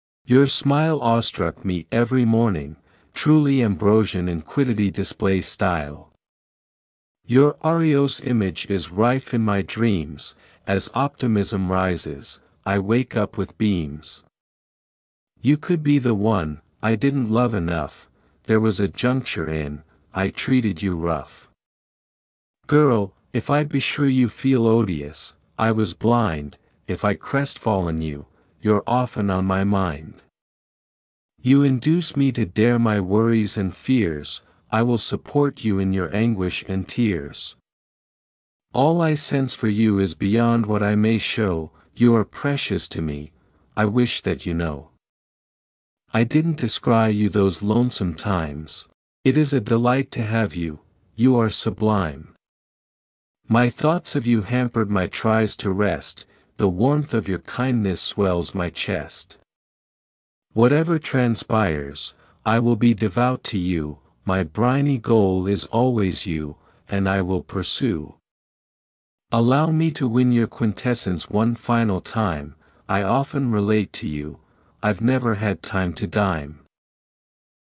Its beautiful words are accompanied very nicely by the haunting melody.